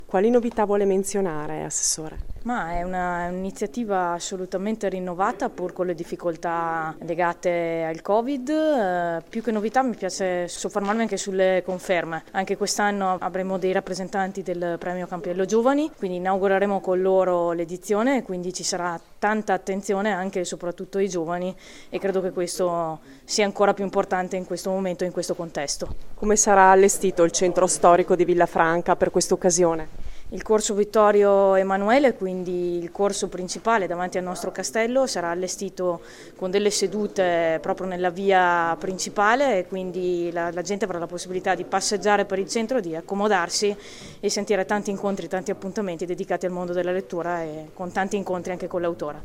Ne hanno parlato al microfono della nostra corrispondente
l’assessore alla Cultura Claudia Barbera